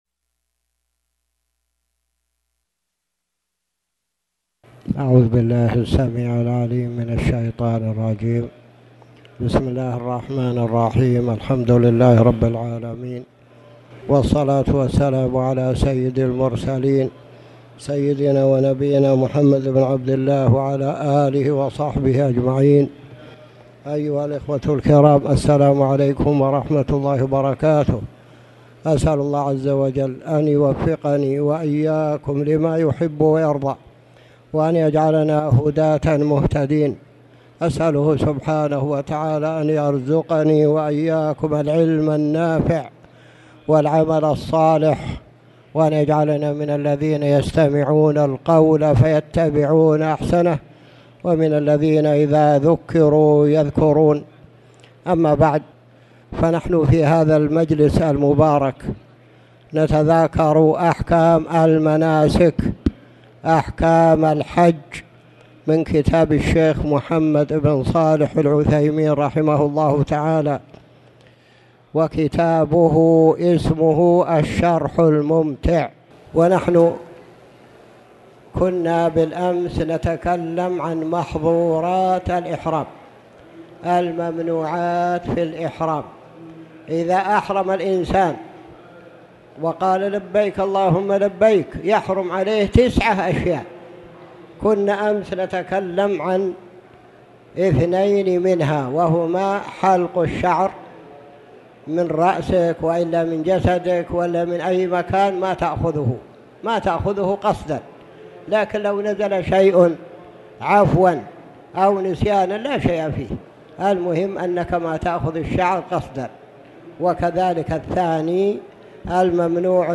تاريخ النشر ٢٣ ذو القعدة ١٤٣٨ هـ المكان: المسجد الحرام الشيخ